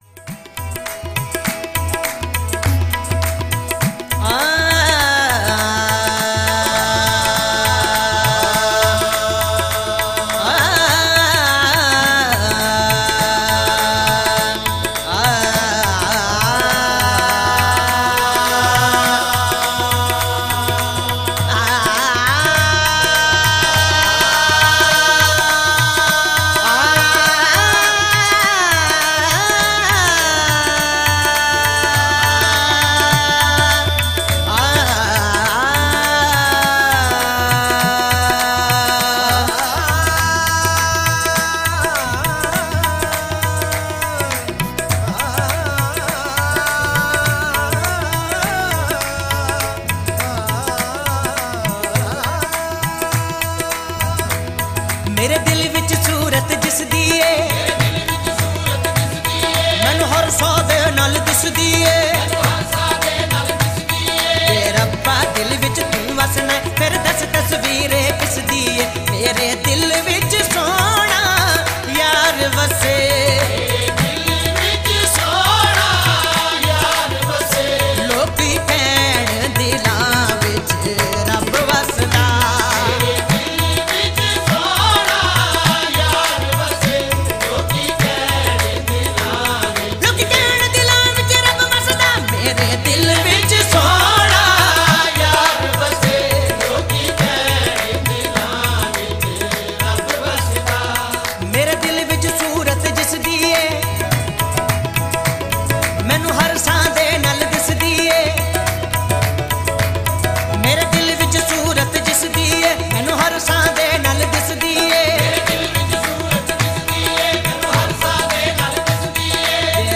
Punjabi Qawwali and Sufiana Kalam